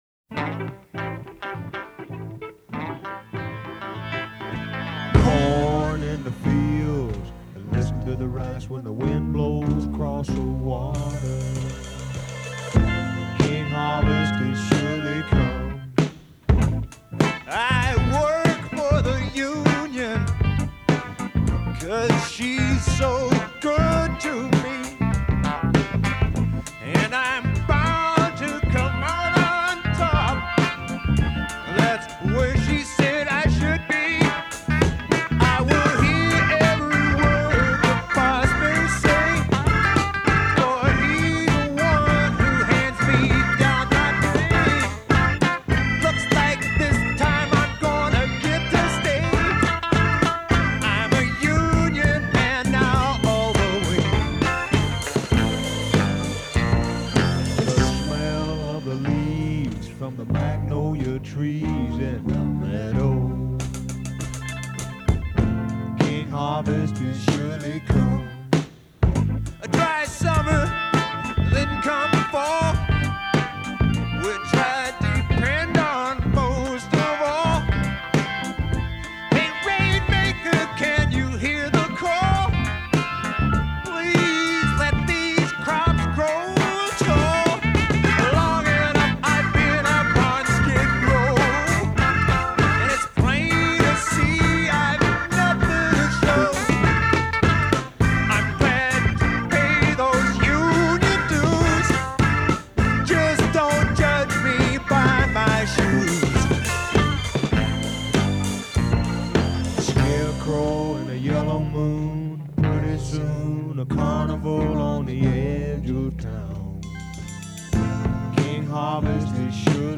The playing on it is superb. Loose and funky.